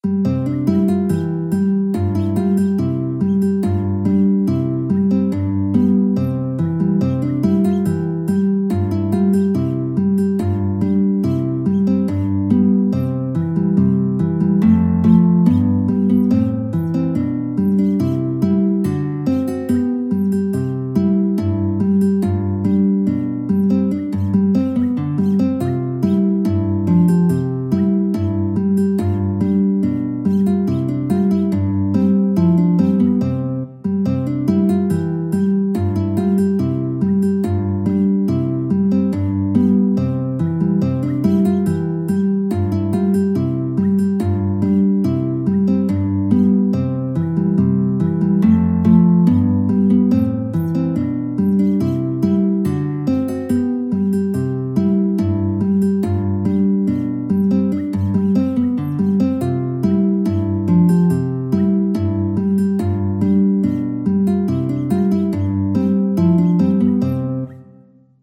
4/4 (View more 4/4 Music)
Arrangement for Guitar solo in standard and tab notation
Guitar  (View more Easy Guitar Music)
Traditional (View more Traditional Guitar Music)
Fingerpicking Music for Guitar